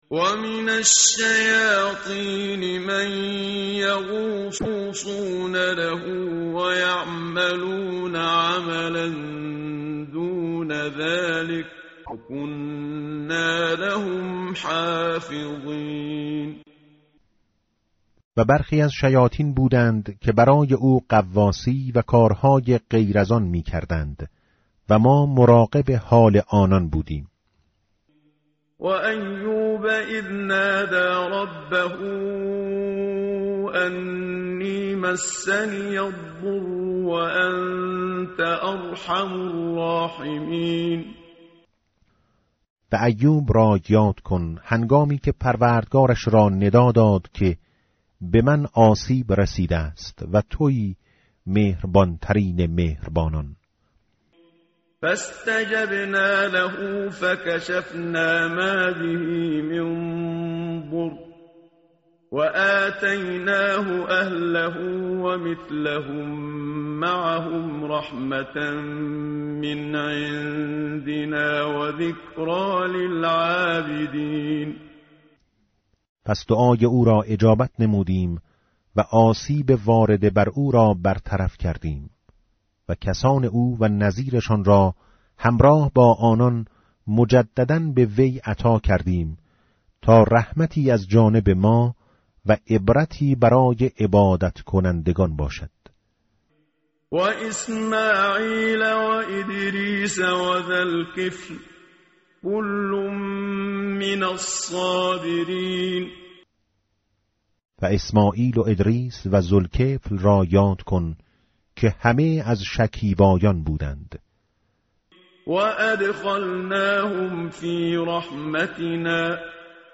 متن قرآن همراه باتلاوت قرآن و ترجمه
tartil_menshavi va tarjome_Page_329.mp3